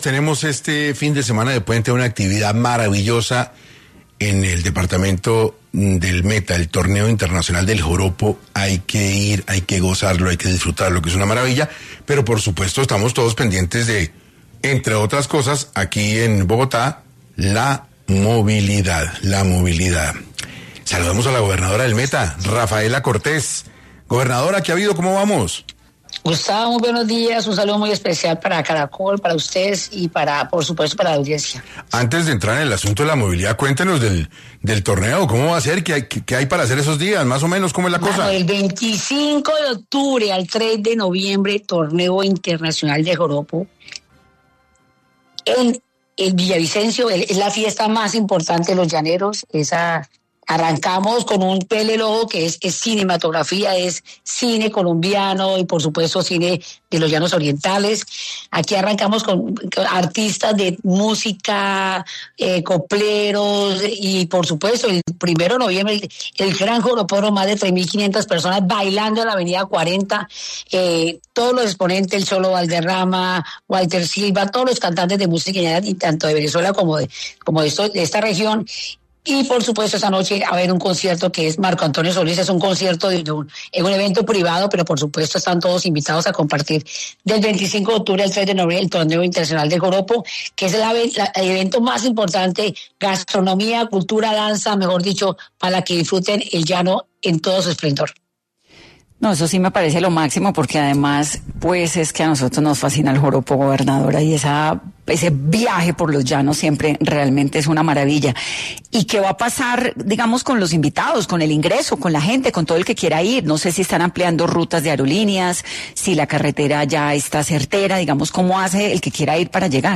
Rafaela Cortés, gobernadora del Meta, pasó por 6AM para hablar de la movilidad en la Vía al Llano, en el marco del Torneo Internacional del Joropo.